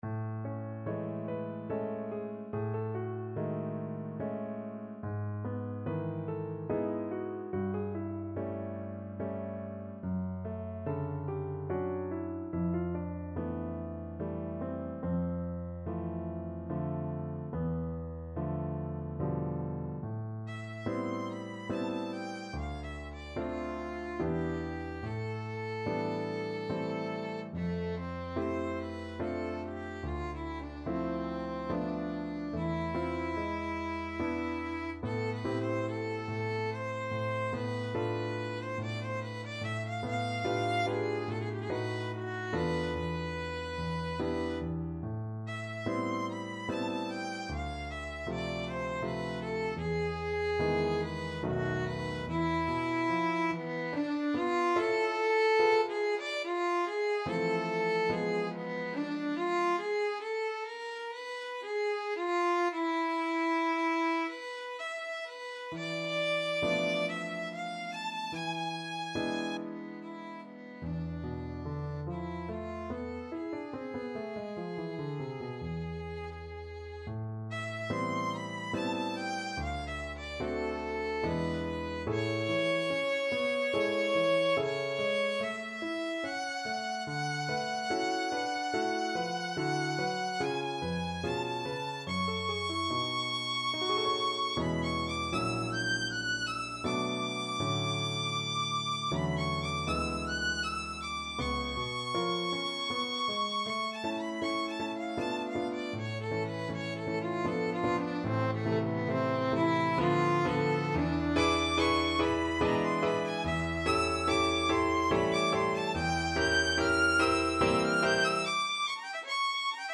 Violin
A minor (Sounding Pitch) (View more A minor Music for Violin )
3/4 (View more 3/4 Music)
Andante =72
Classical (View more Classical Violin Music)